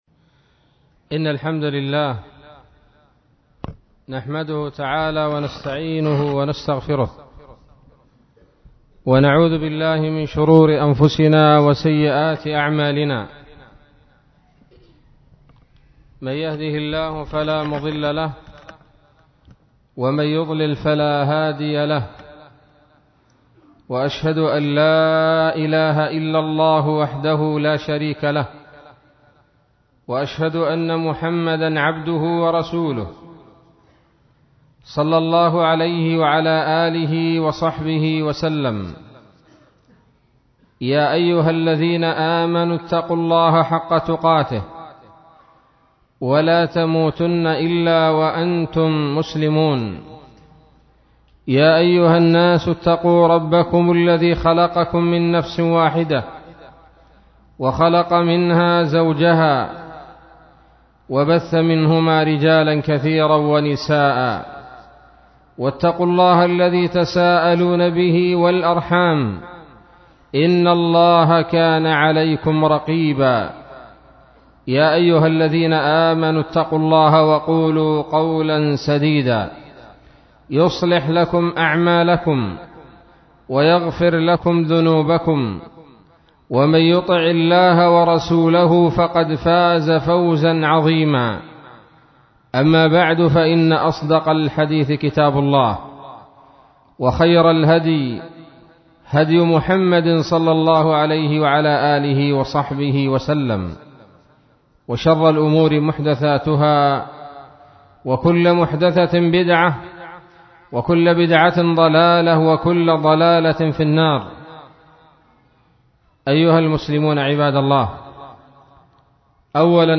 محاضرة بعنوان :((نصائح لطلاب العلم